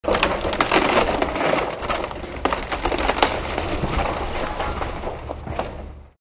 Звуки ударов, разрушения
Звук разрушения здания бульдозер сносит стены